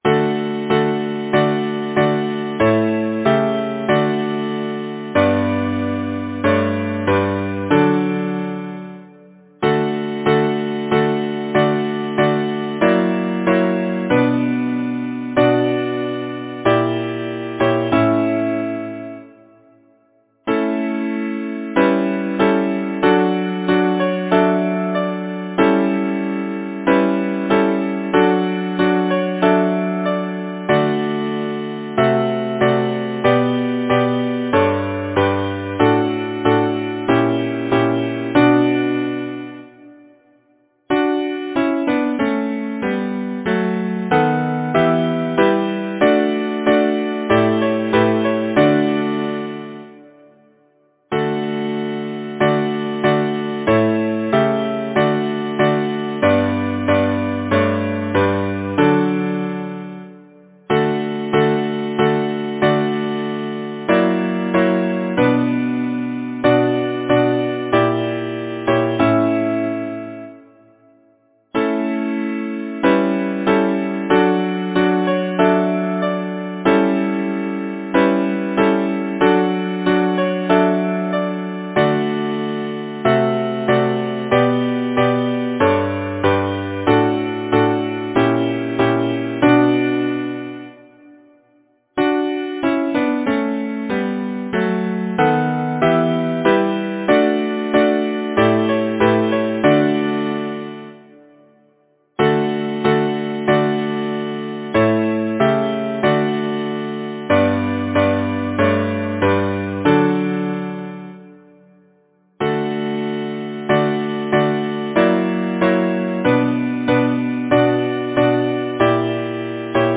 Title: The Birthday Composer: George Alexander Osborne Lyricist: Robert West Taylor Number of voices: 4vv Voicing: SATB Genre: Secular, Partsong
Language: English Instruments: A cappella